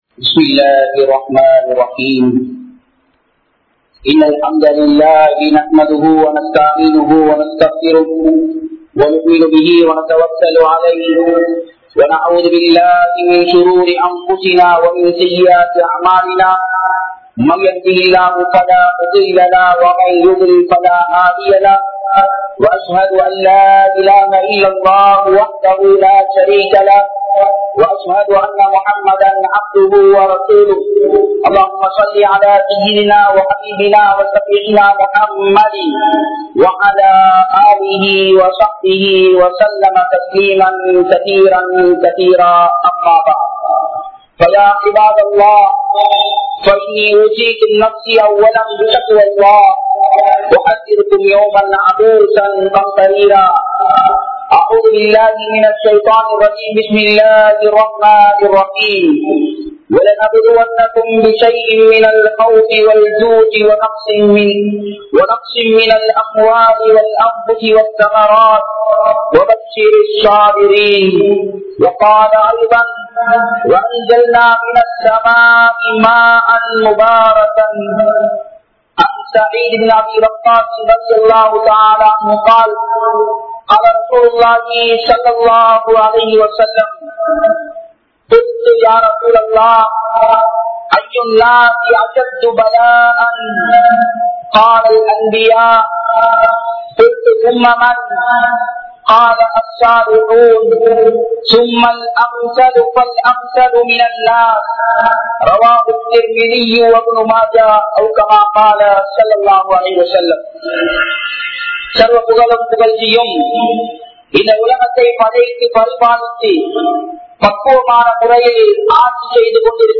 Soathanaihaluku Evvaaru Muham Koduppathu? (சோதனைகளுக்கு எவ்வாறு முகம் கொடுப்பது?) | Audio Bayans | All Ceylon Muslim Youth Community | Addalaichenai
Wellampitiya, Barandia Watta Adhnan Jumua Masjith